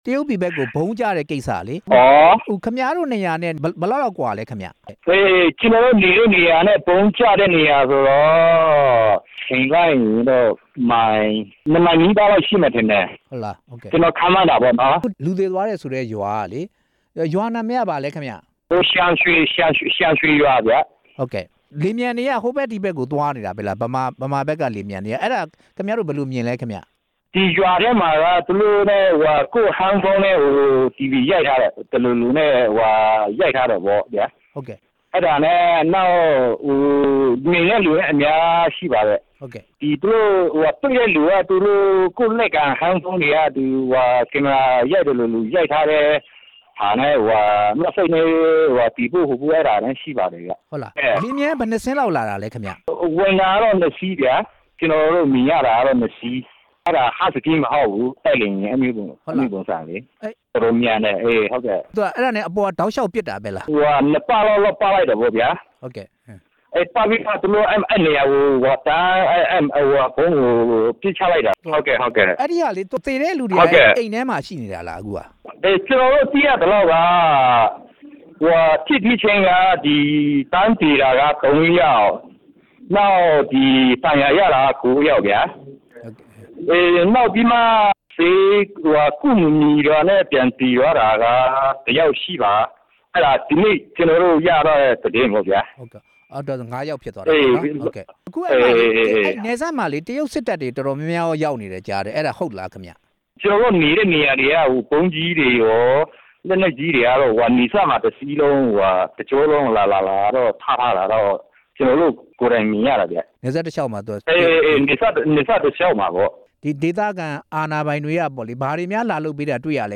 တရုတ်ဘက်ဗုံးကျမှု ဒေသခံ တစ်ဦးနဲ့ မေးမြန်းချက်